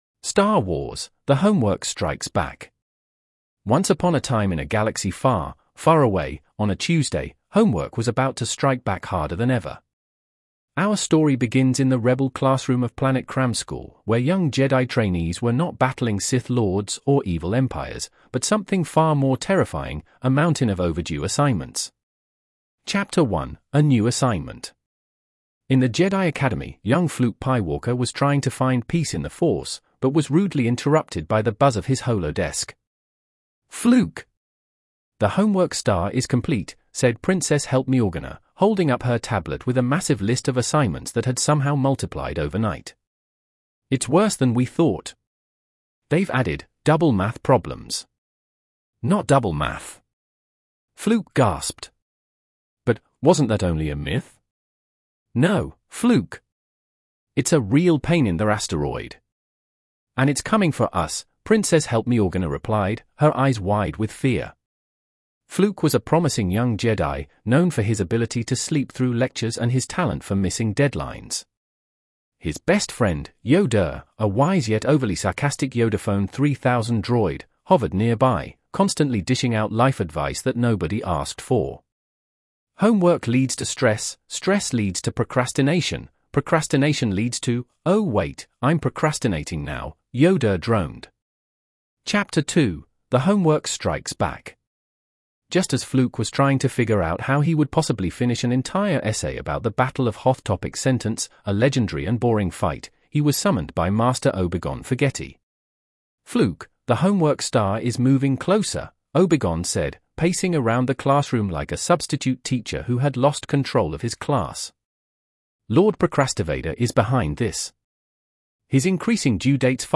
Thanks AI